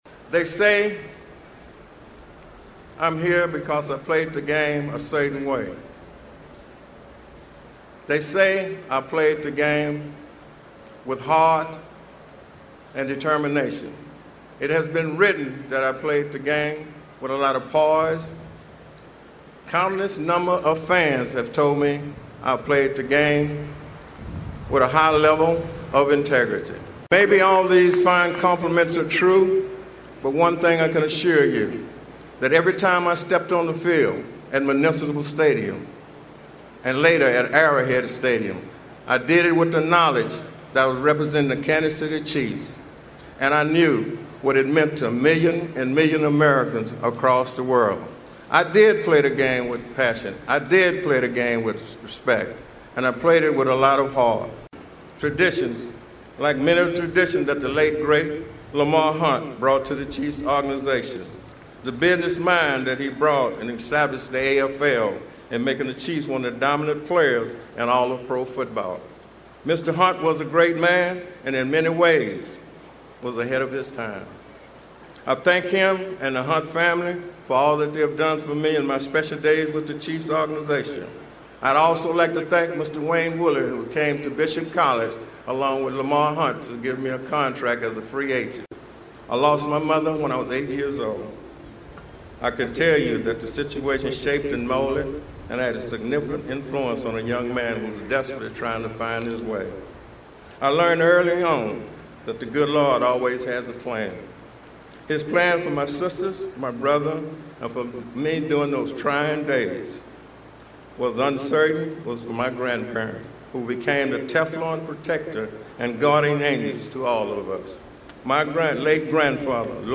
Emmitt Thomas joined the best of the best Saturday as he was inducted into pro football’s hall of fame in a ceremony on Saturday. Listen to portions of Thomas’ speech.